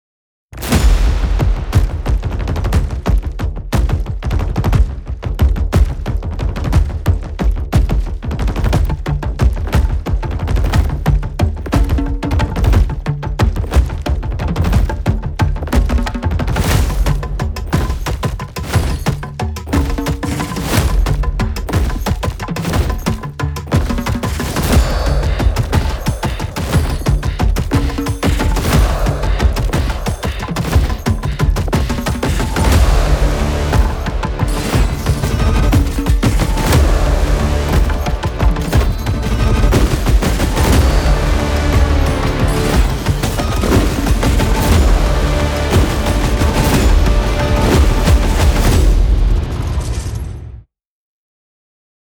Tribal drums – cinematic folk percussion